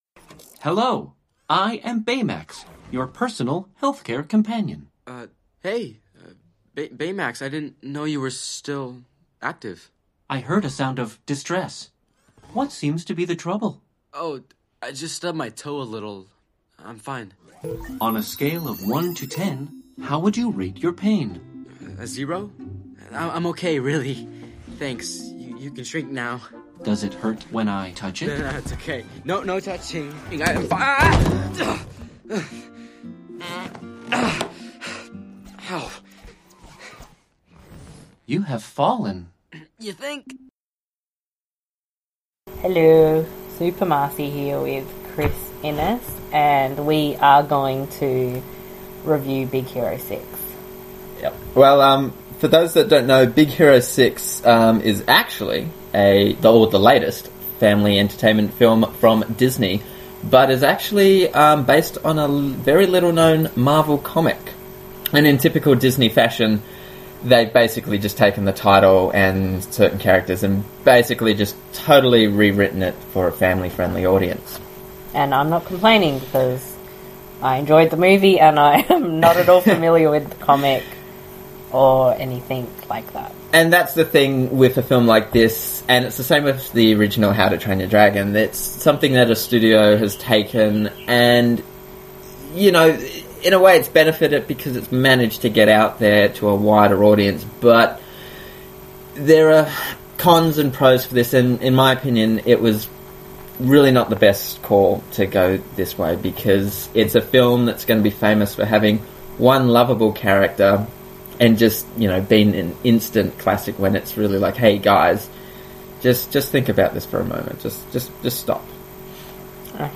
Instead of writing separate reviews, we thought it might be fun to review the film together in audio format.
big-hero-6-audio-review.mp3